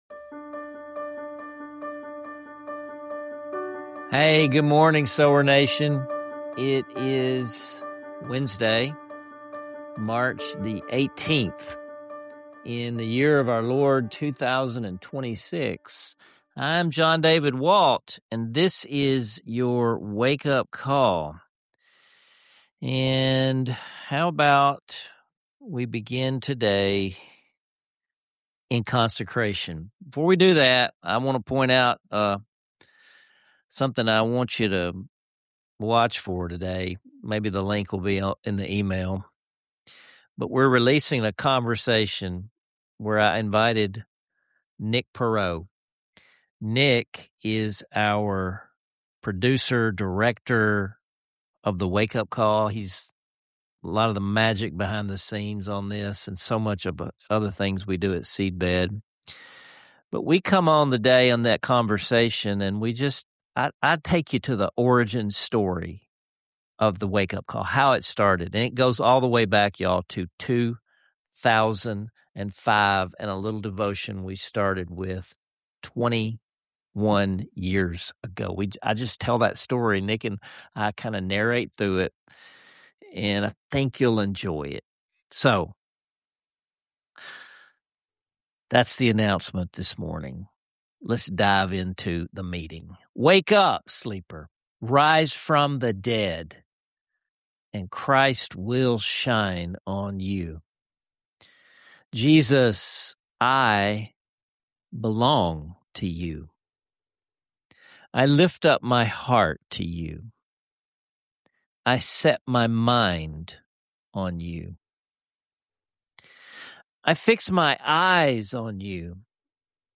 If you’ve ever felt stuck in your faith or wondered how to know if you’re truly growing, this episode will encourage you to engage with your journey on a deeper level. Don’t miss this unique blend of teaching, music, and heartfelt conversation.